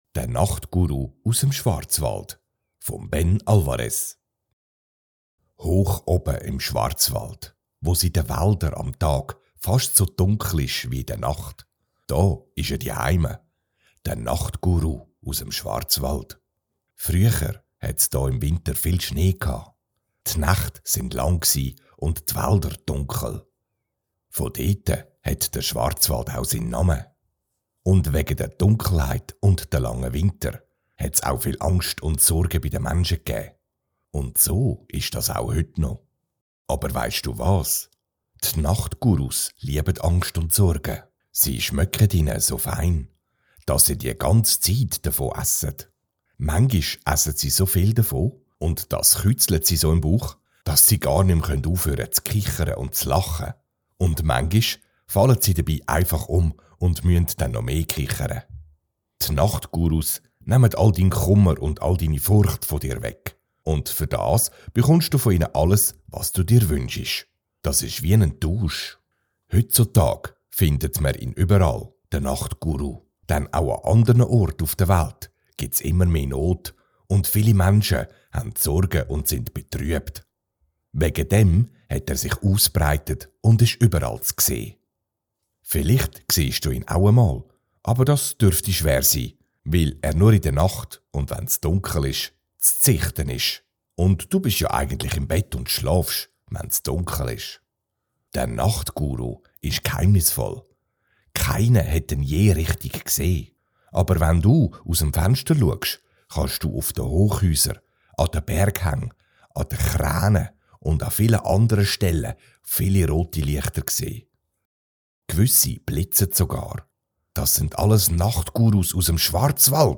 Nachtguru_vom_Schwarzwald_Schweizerdeutsch.mp3